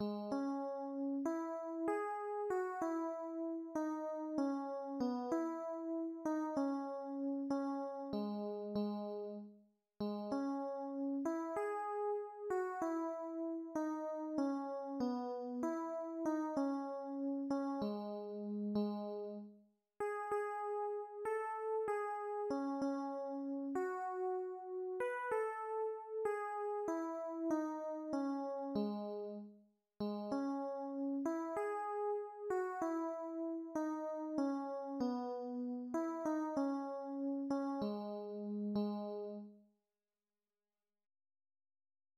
Műfaj magyar népdal
Hangfaj fríg
A kotta hangneme H fríg
Előadásmód Moderato
Előadási tempó 96